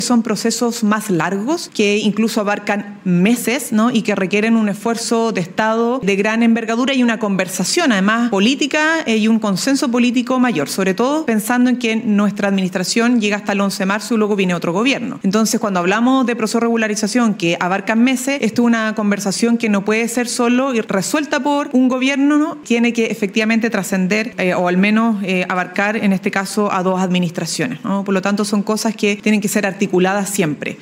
CUNA-VOCERA.mp3